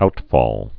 (outfôl)